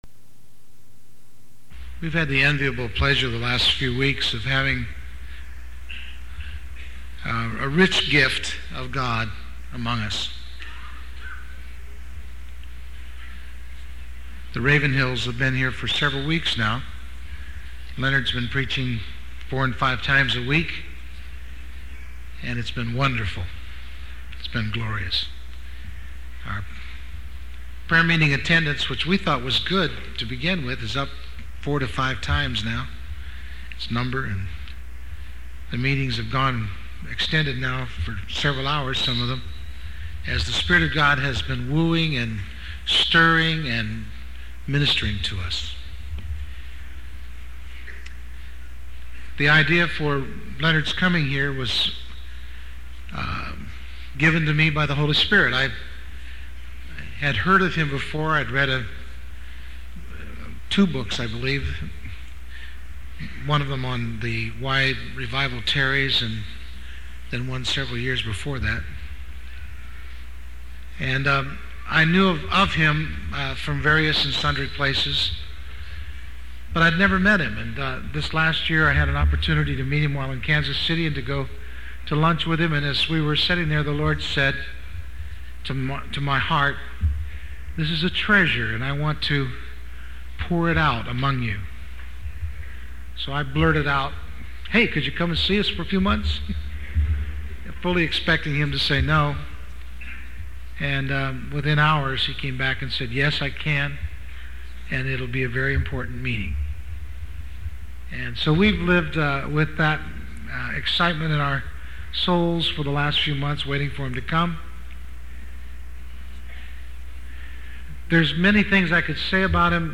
In this sermon, the speaker begins by describing a vision in which the posts of a door moved and the house was filled with smoke. He then acknowledges his own unworthiness and sinful nature in the presence of the Lord.